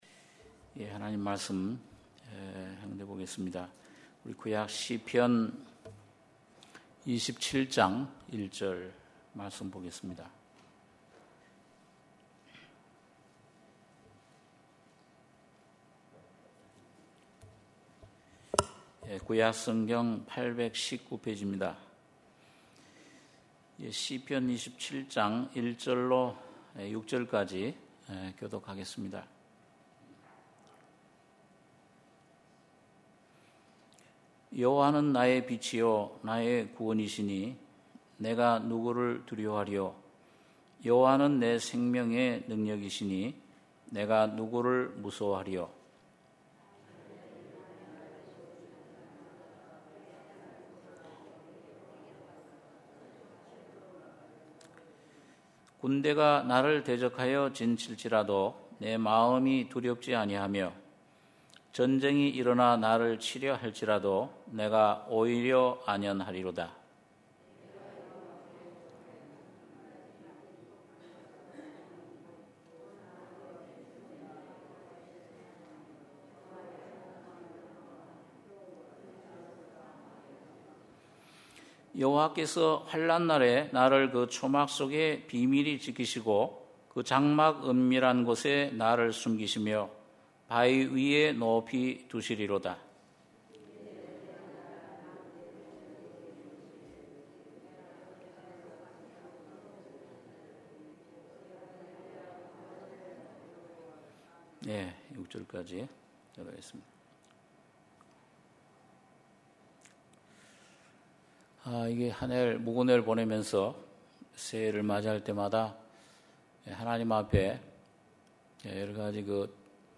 주일예배 - 시편 27장 1절 - 6절 2부